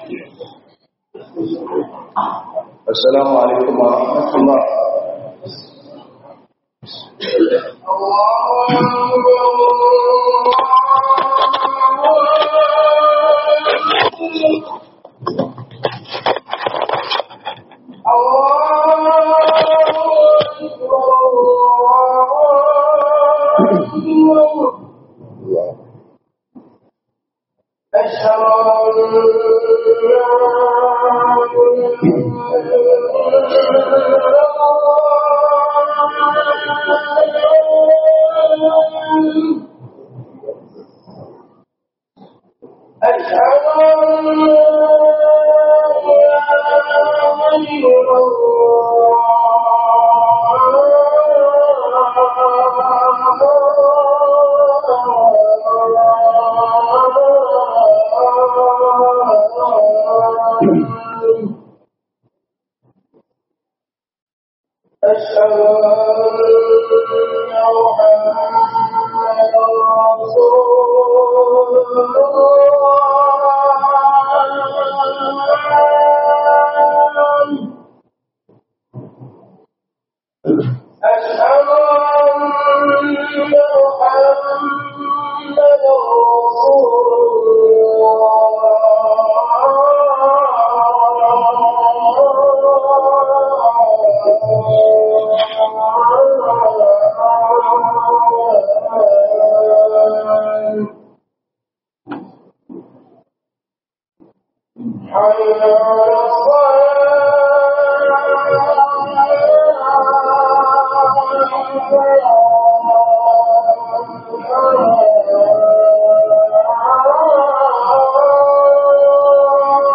hudubar Juma'a